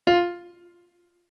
MIDI-Synthesizer/Project/Piano/45.ogg at 51c16a17ac42a0203ee77c8c68e83996ce3f6132